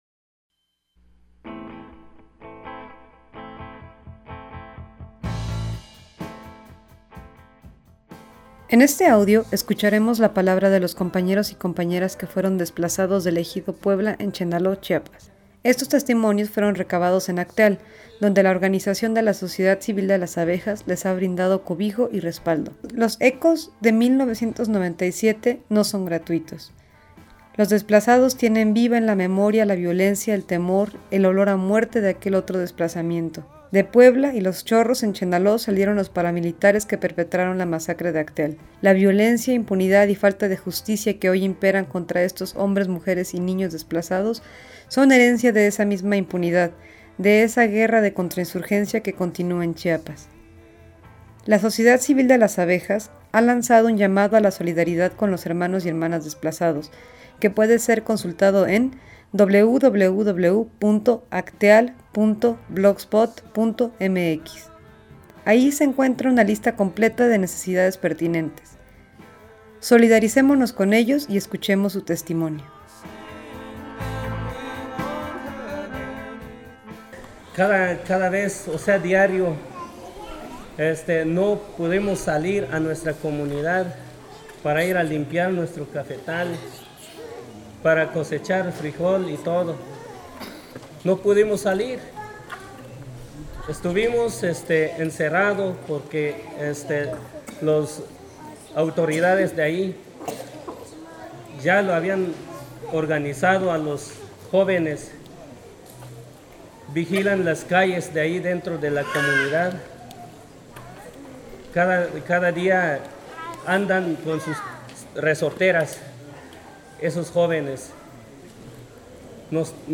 Testimonios de hombres y mujeres desplazados del Ejido Puebla, Chenalhó
En este audio escucharemos la palabra de los compañeros y compañeras que fueron desplazados del Ejido Puebla en Chenalhó, Chiapas. Estos testimonios fueron recabados en Acteal donde la organización de la Sociedad Civil de Las Abejas les ha brindado cobijo y respaldo.